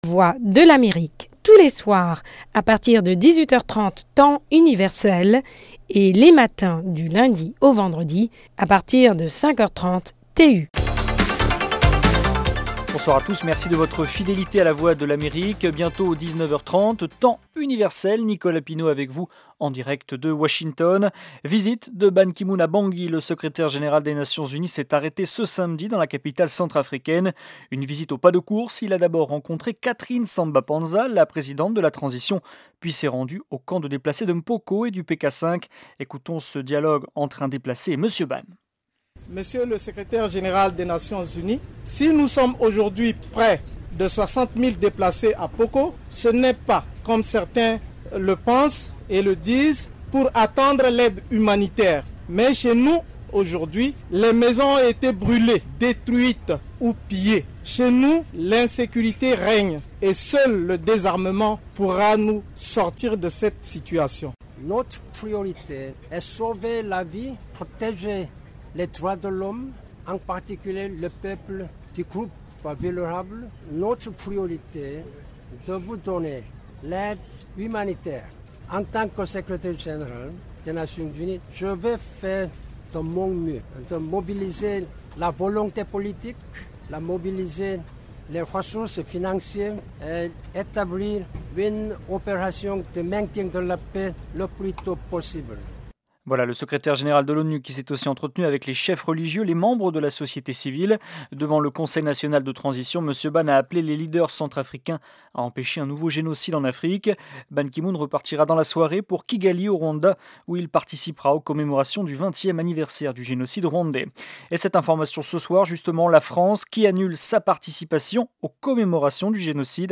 Le Magazine au Féminin de la VOA diffusé le 5 avril 2014:Le Sous-secrétaire d’Etat adjoint aux Affaires africaines, David Gilmour, parle du programme YALI, l’Initiative pour les Jeunes Leaders Africains, initié par le président Obama. Dans une interview pour LMF, il a dit que les filles bénéficieront d’une attention particulière dans la sélection de 500 jeunes boursiers en juin prochain.
Les principales victimes des mines sont généralement les enfants. LMF commence au bout de 5mn d'info du jour.